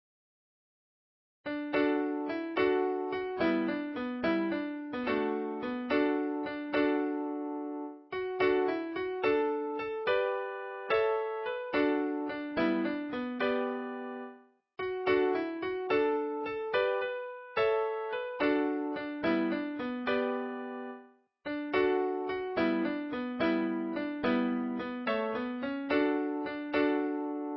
Inst=Instrumental (based on a MIDI file)
The Persian’s Crew Ballad HTML Page